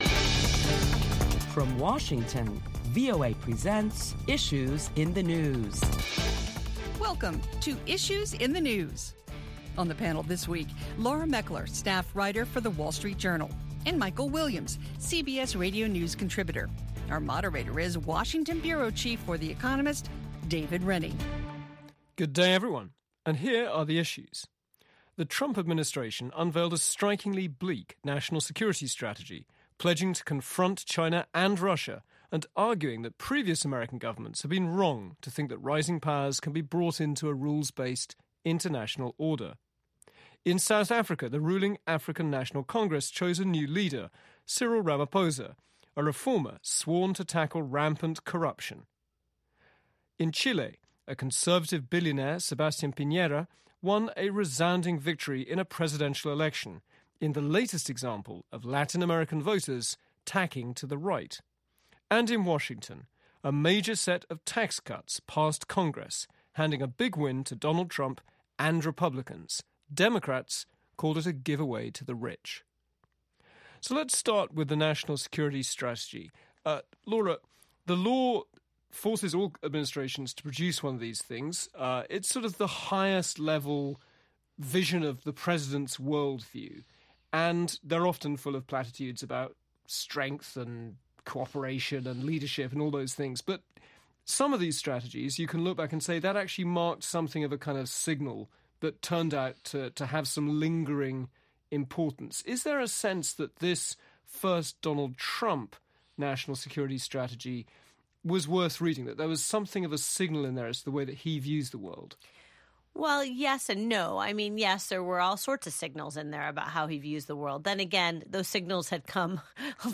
discusses the week's top stories